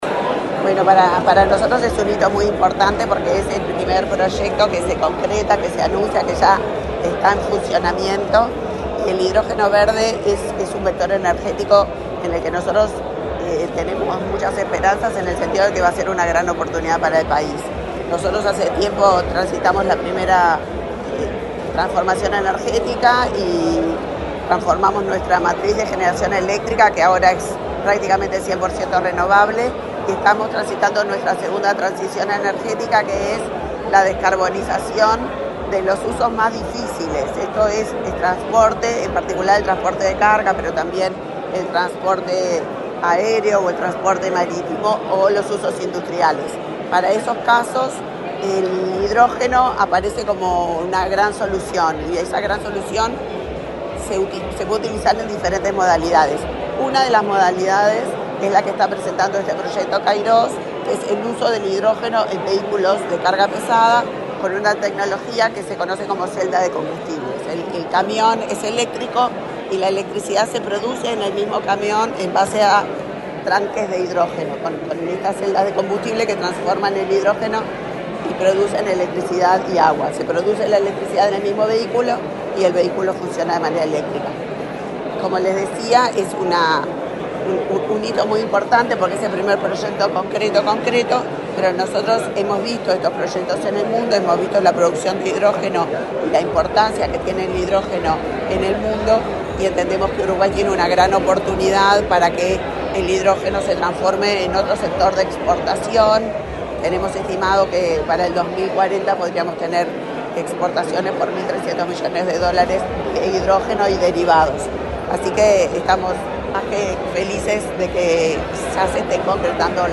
Declaraciones de la ministra de Industria, Elisa Facio
Este miércoles 30 en el Laboratorio Tecnológico del Uruguay, la ministra de Industria, Elisa Facio, dialogó con la prensa, luego de participar en el